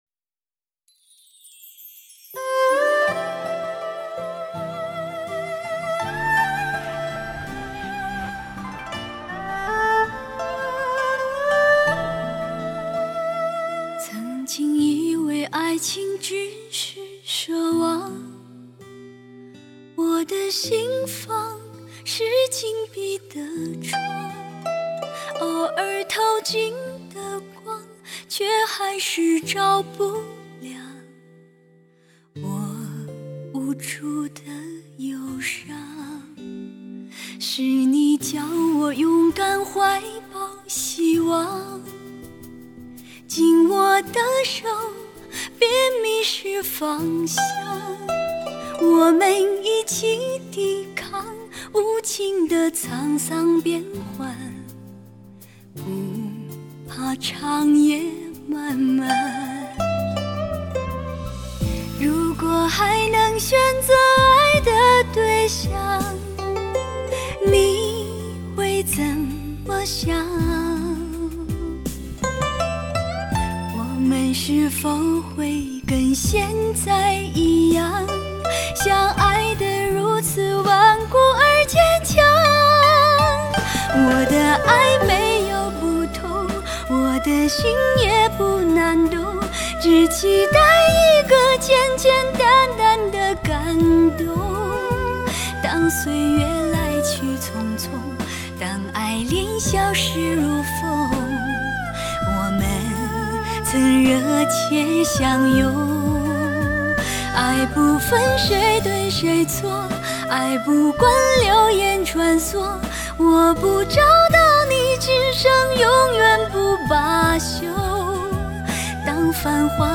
笛子 竹埙 排箫
二胡
古筝
无懈可击纯美女声，古典唱法演绎前卫曲风，糅合古典与时尚多种音乐元素，独具特色和风韵。